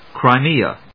Cri・me・a /krɑɪmíːə‐míə/